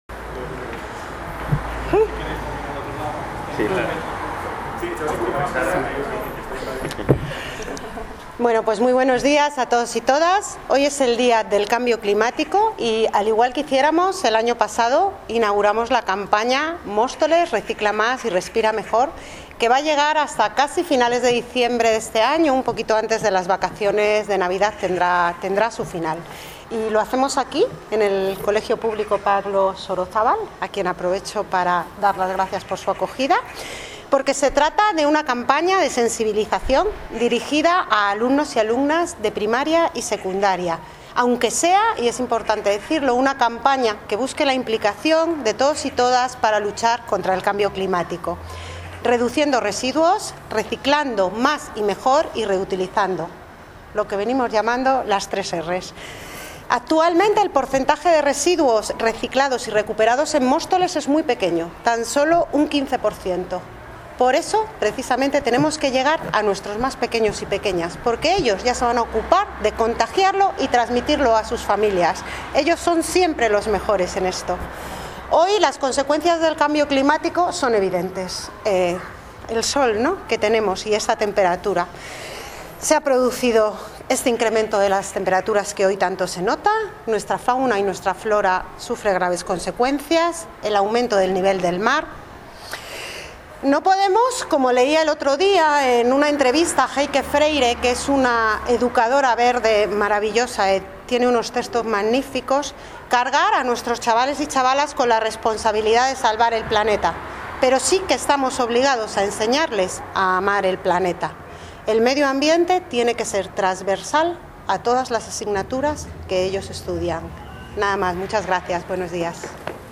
Audio - María Isabel Cruceta (Concejala de Educación) Sobre Campaña Reciclaje